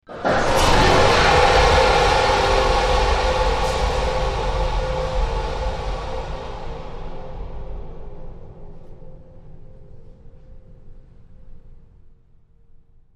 Shock Fire; Warning Beeps, Heavy Power Surge And Whoosh